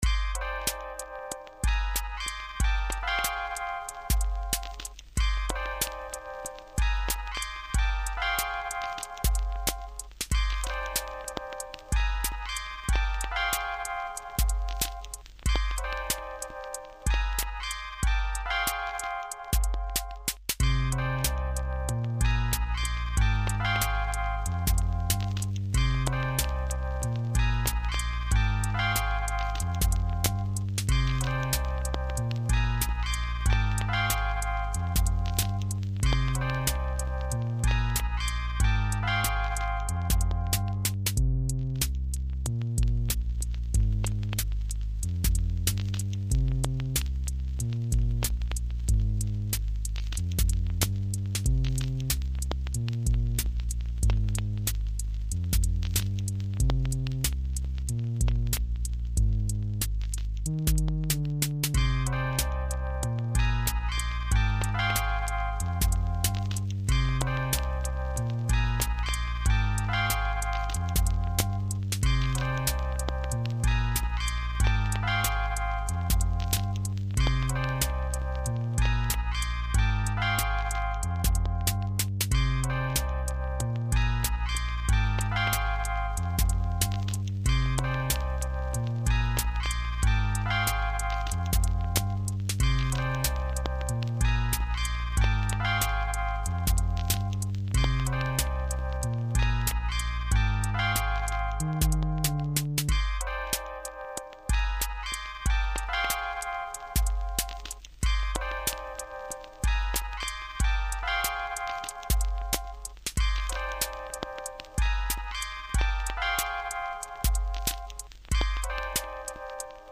mp3,4850k] Рэп